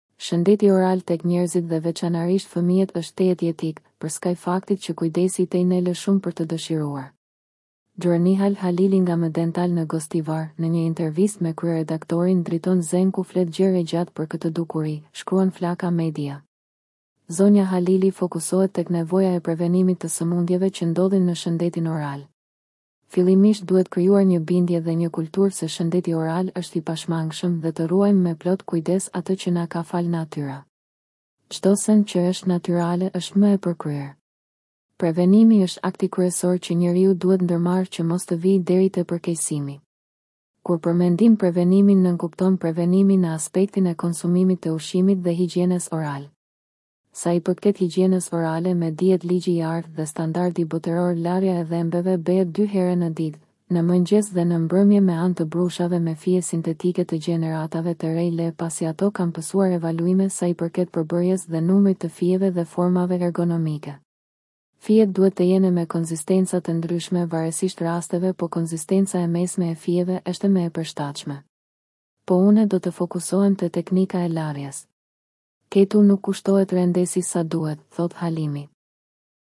intervistë